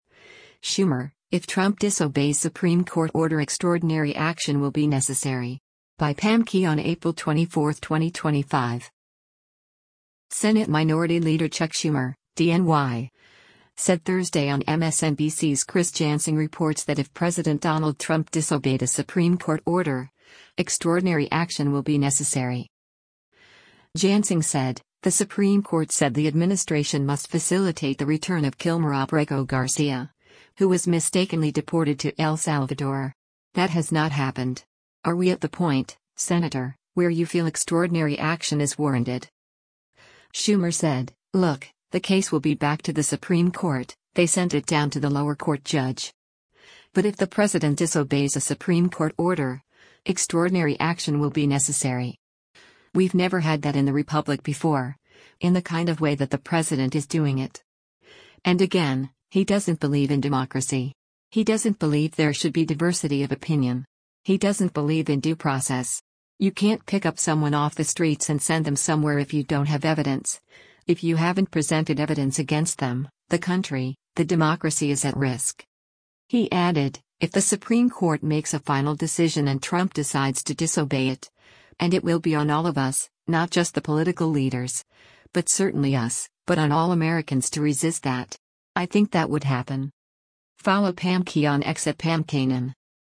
Senate Minority Leader Chuck Schumer (D-NY) said Thursday on MSNBC’s “Chris Jansing Reports” that if President Donald Trump disobeyed a Supreme Court order, “extraordinary action will be necessary.”